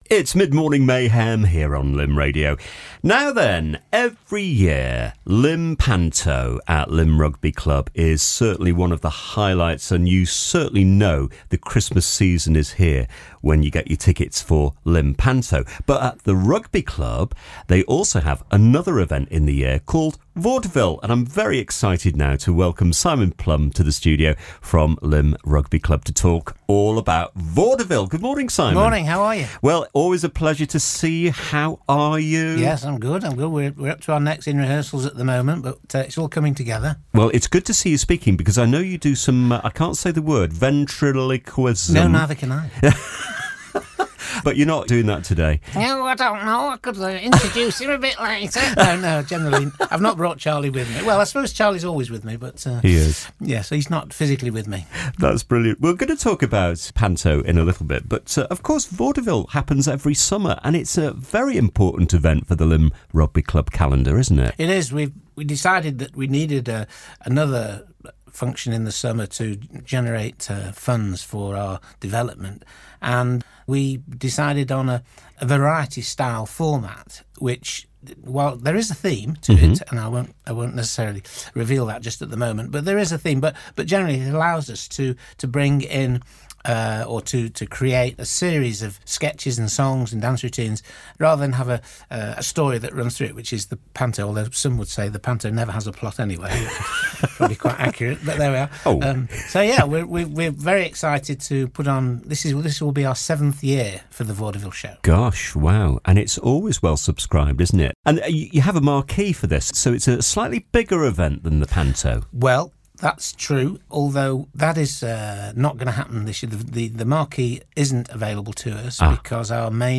Vaudeville-Lymm-Radio.mp3